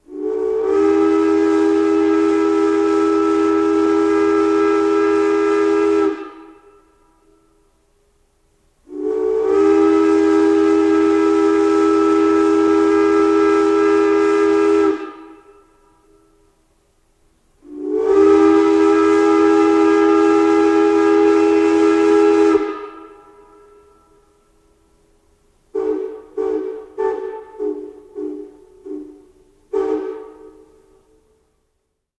Звуки тепловоза
Продолжительный гудок тепловоза